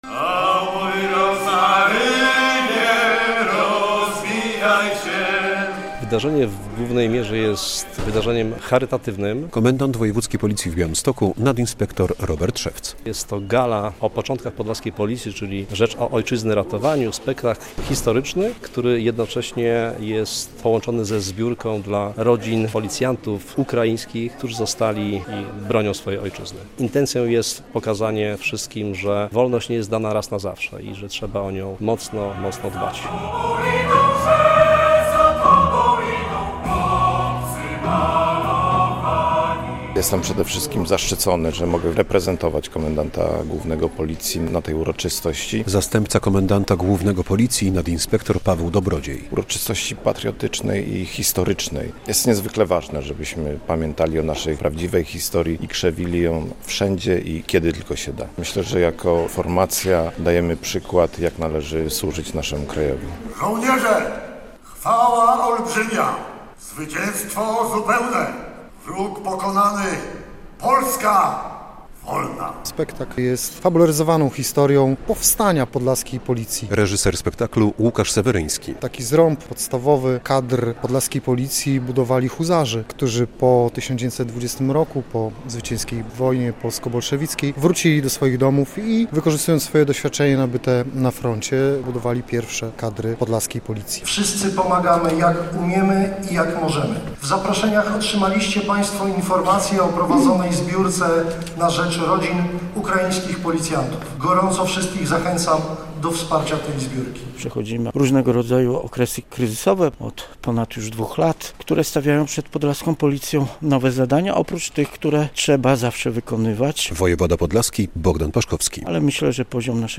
Gala "Początki podlaskiej Policji, czyli rzecz o Ojczyzny ratowaniu" - relacja
Spektakl wystawiono na deskach Opery i Filharmonii Podlaskiej w Białymstoku.